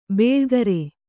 ♪ bēḷgare